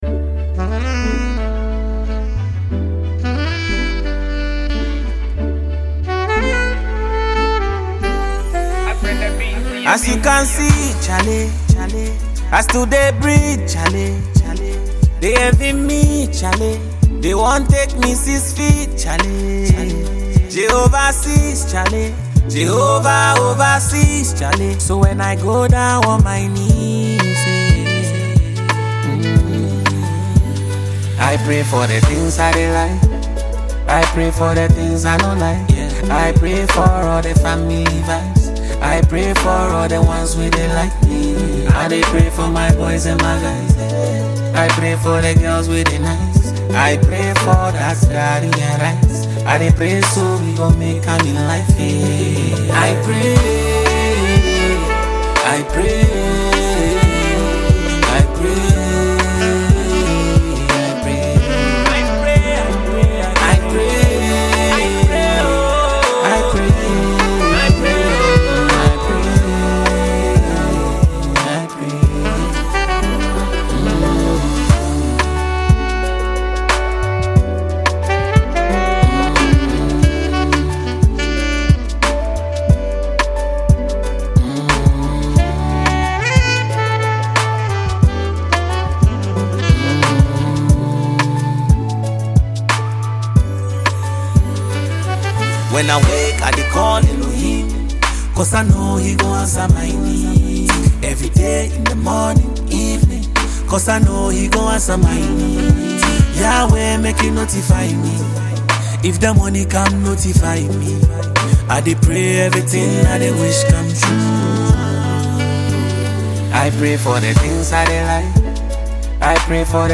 Award-winning Ghanaian musical duo